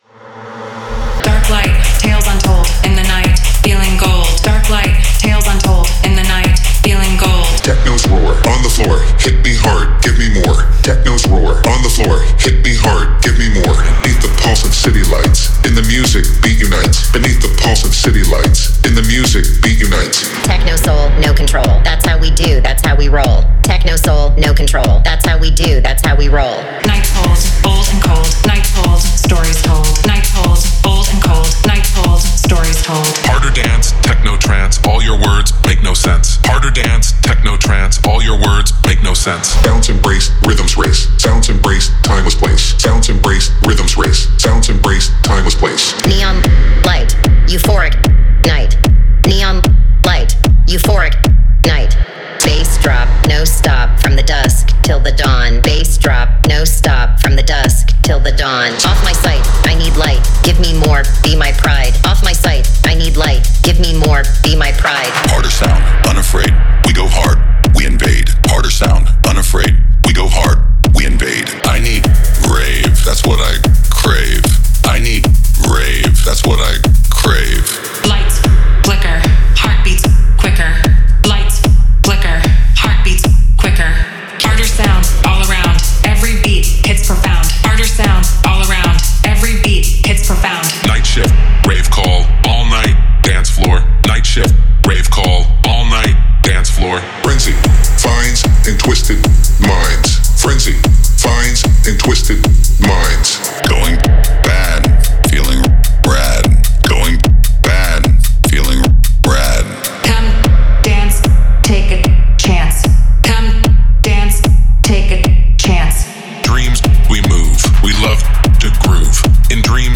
テクノ系サンプルパックをご紹介いたします。
オールドスクールな雰囲気と新しいサウンドが融合し、誰もが話題にするジャンルとなっています。
長いフレーズ、短いフレーズ、ワンショットなど、5つのトーンを持つさまざまなボーカルループを提供します。
・ウェットロングボーカルフレーズ65選
・ウェットなショートボーカルフレーズ65選